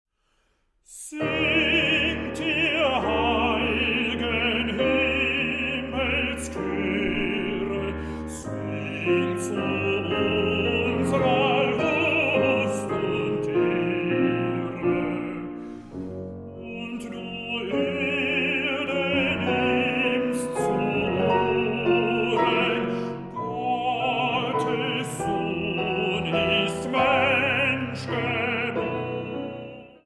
Bariton
Klavier